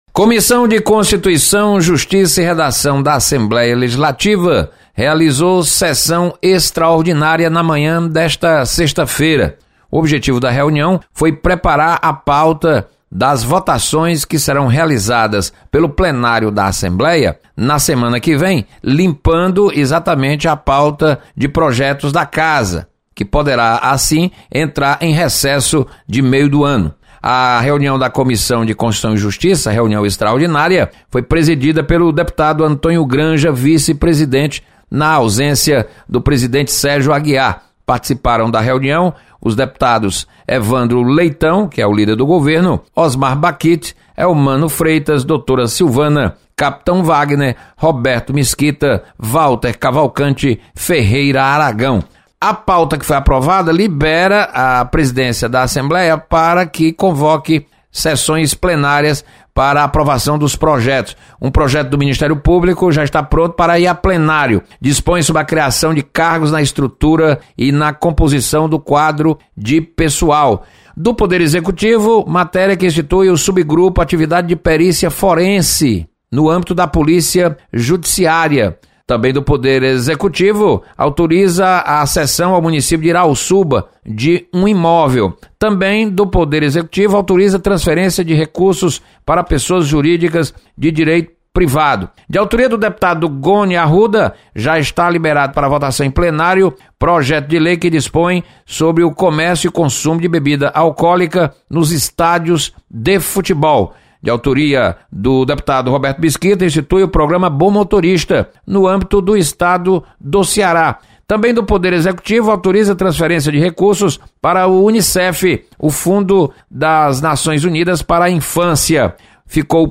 Deputado Renato Roseno defende ampliação da fiscalização à venda e uso de agrotóxicos. Repórter